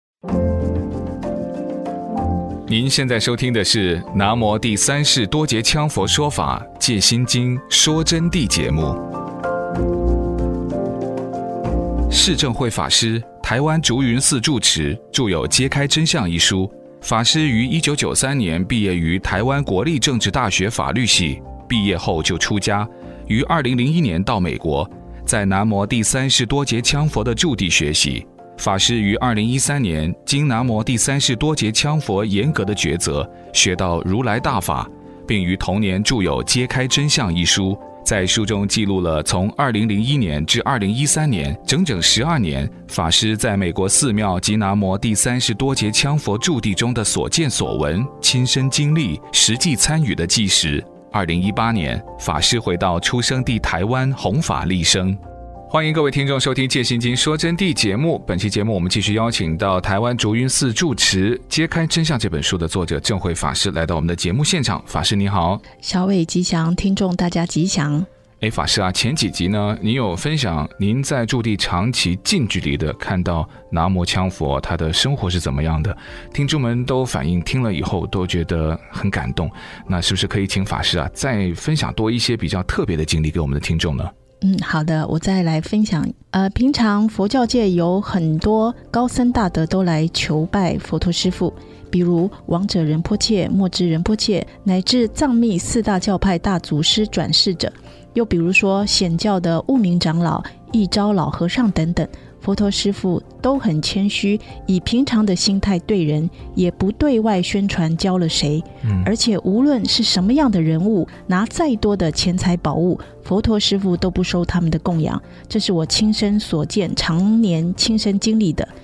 佛弟子访谈（四十六）南无羌佛德境圣洁高超，圣迹预言，开口即应